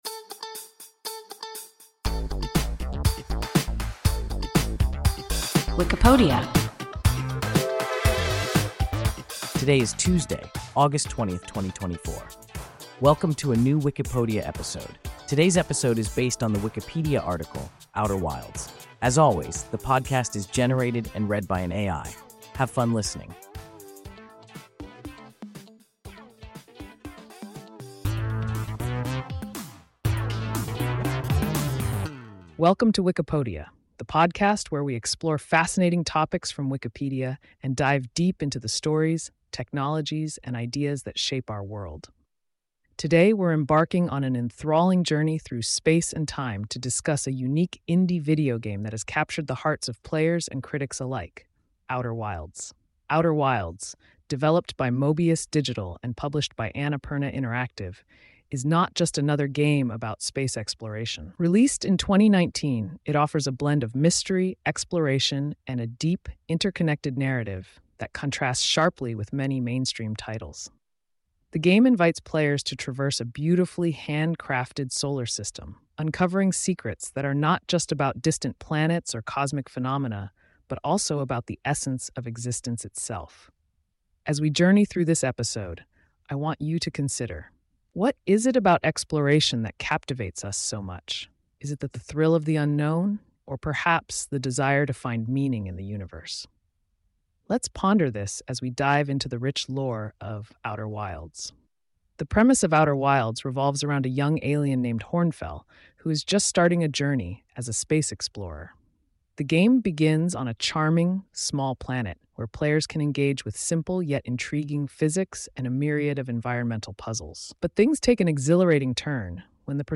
Outer Wilds – WIKIPODIA – ein KI Podcast